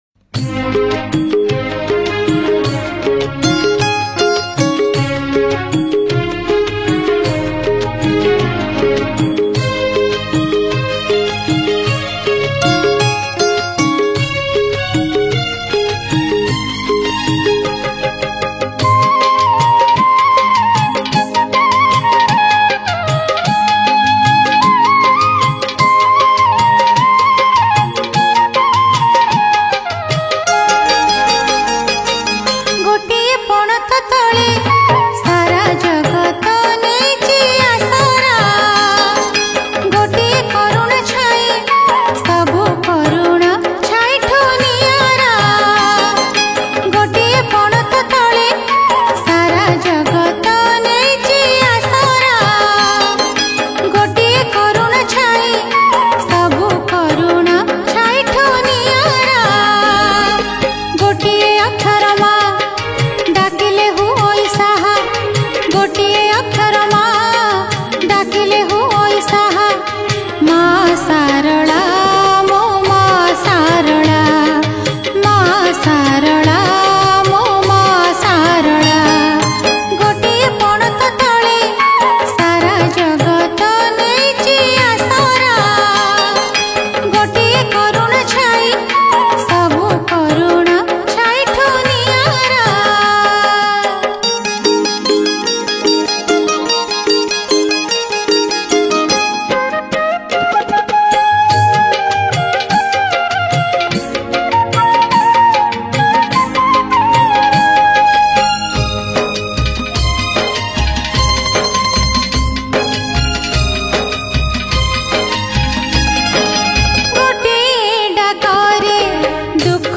Category: New Odia Bhakti Songs 2022